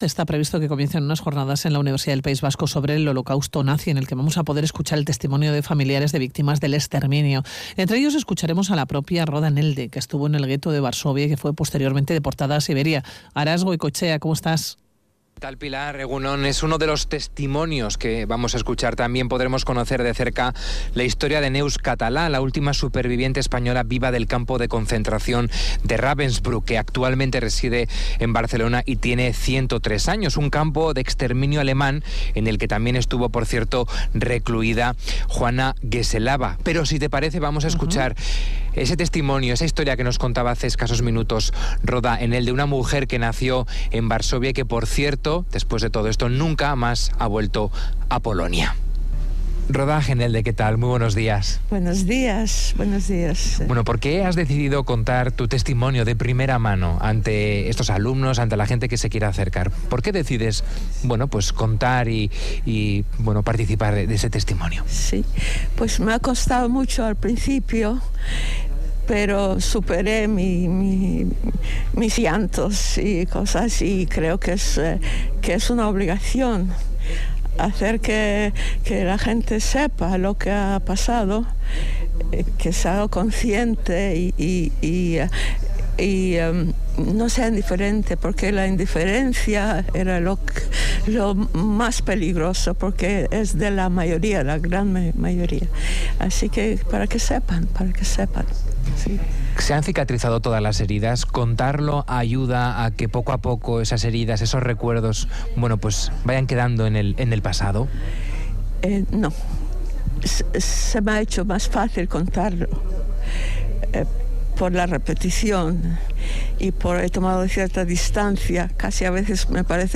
testimonio de una víctima del Holocausto